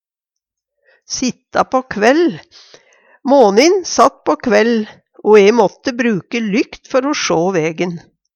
sitta på kvell - Numedalsmål (en-US)